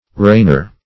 reigner - definition of reigner - synonyms, pronunciation, spelling from Free Dictionary Search Result for " reigner" : The Collaborative International Dictionary of English v.0.48: Reigner \Reign"er\ (r?n"?r), n. One who reigns.
reigner.mp3